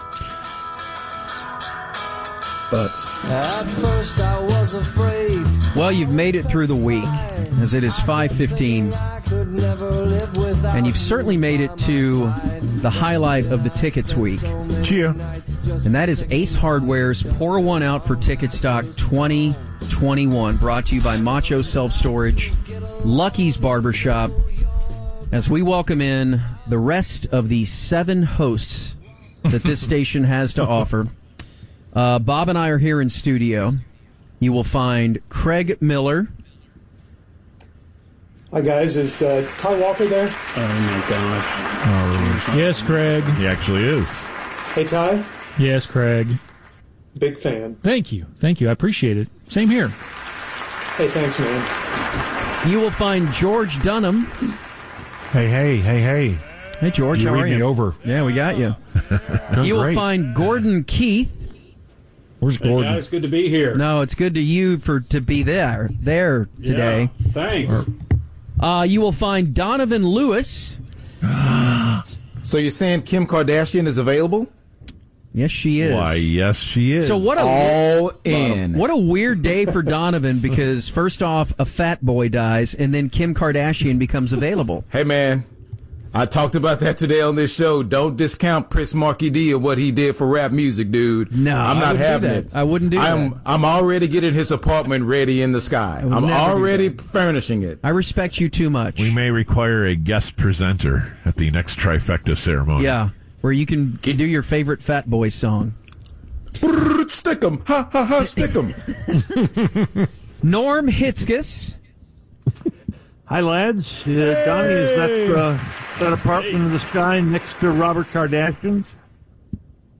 Roundtables of all 9 hosts making the best of what should have been Ticketstock 2021.